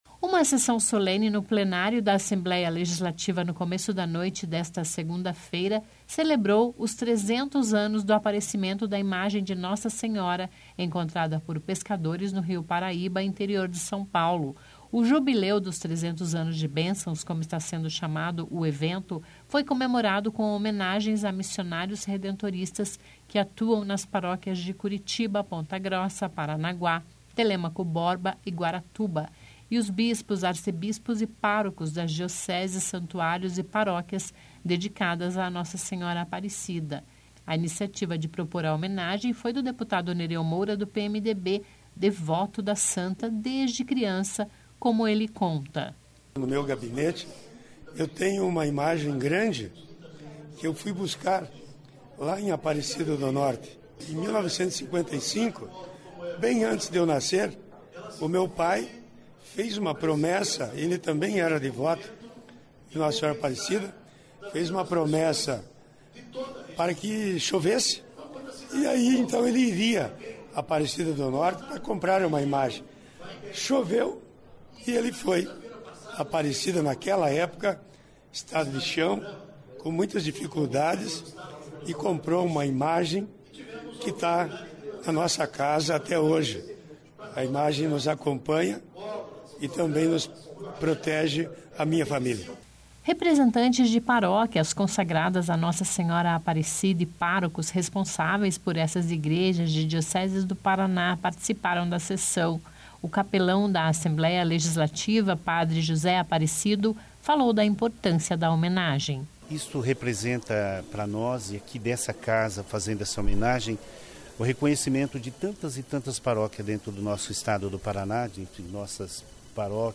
(Descrição do áudio)) Uma sessão solene no Plenário da Assembleia Legislativa no começo da noite esta segunda-feira (9), celebrou os 300 anos do aparecimento da imagem de Nossa Senhora, encontrada por pescadores no rio Paraíba, interior de São Paulo.